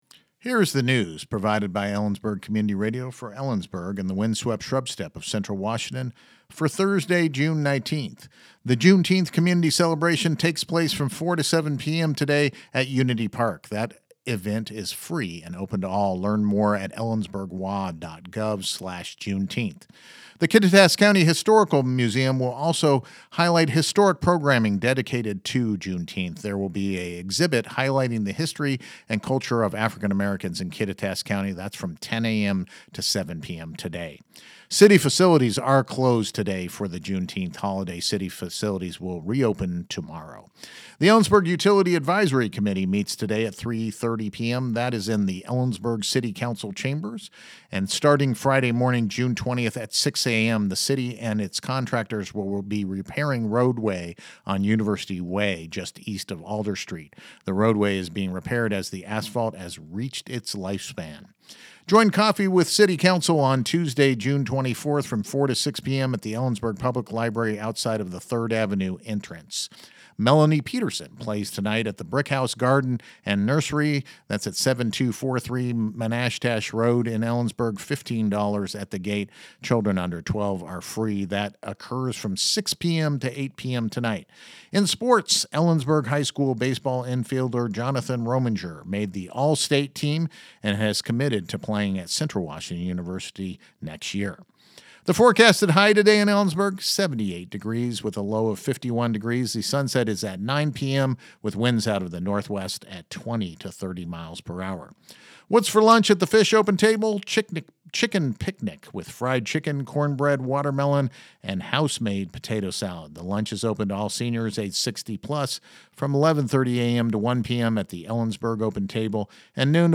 LISTEN TO THE NEWS HERENEWSThe Juneteenth Community Celebration takes place from 4-7 p.m. today, at Unity Park.